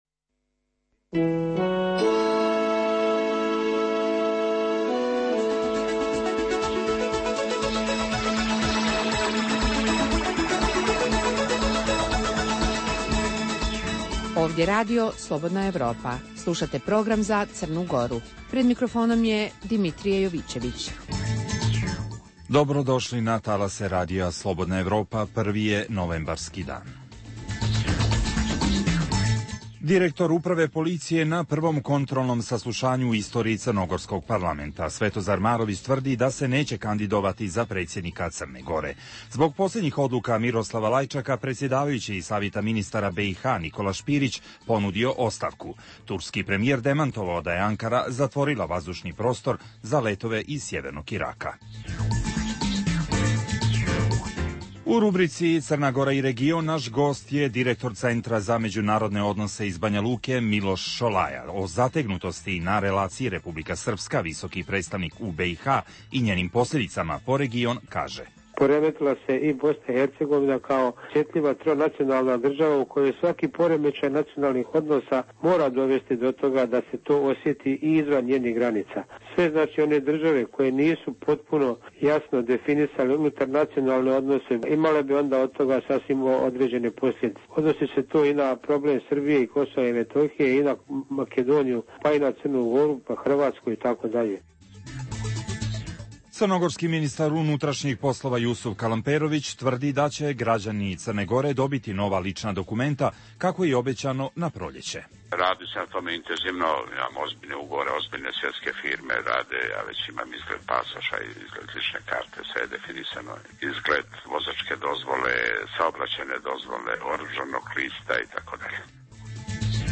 Sadrži lokalne, regionalne i vijesti iz svijeta, tematske priloge o aktuelnim dešavanjima iz oblasti politike, ekonomije i slično, te priče iz svakodnevnog života ljudi, kao i priloge iz svijeta. Redovan sadržaj emisije četvrtkom je intervju, u okviru serijala "Crna Gora i region".